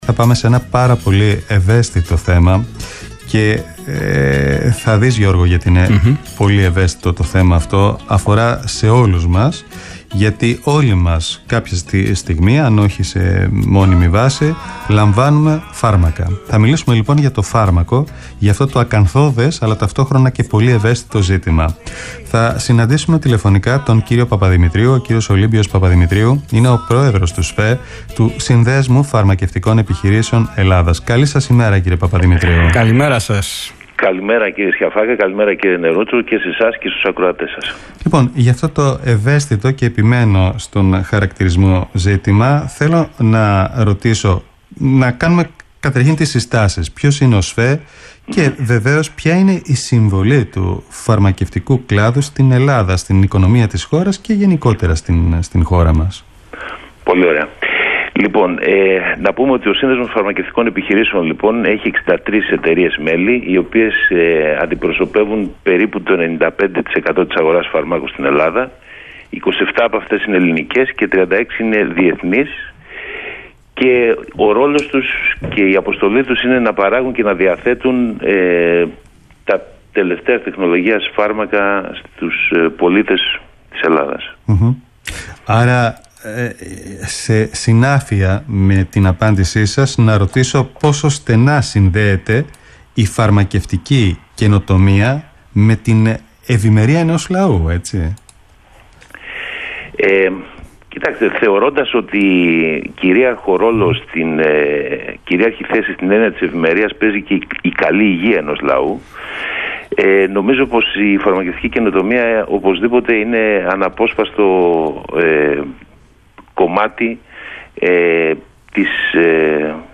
Ηχητικό απόσπασμα από την συνέντευξη